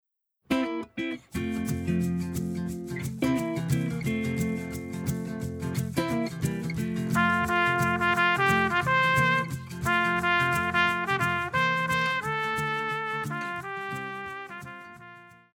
Pop
Trumpet
Band
Instrumental
Rock
Only backing